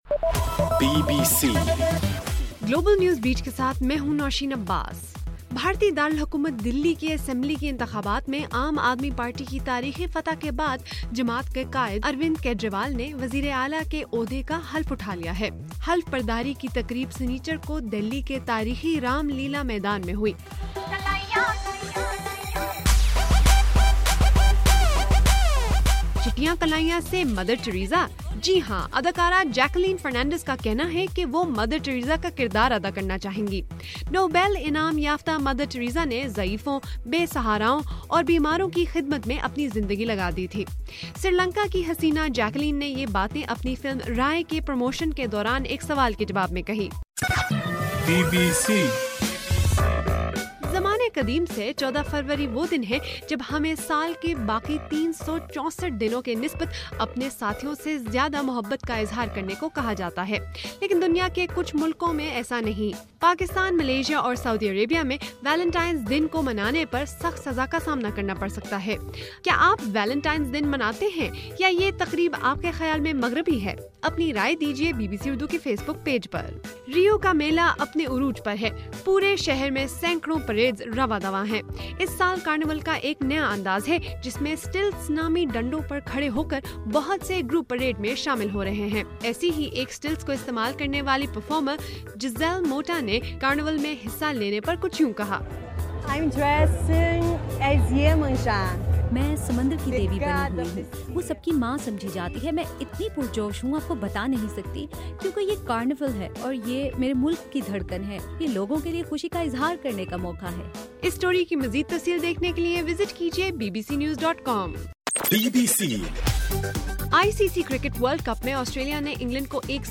فروری 14: رات 12 بجے کا گلوبل نیوز بیٹ بُلیٹن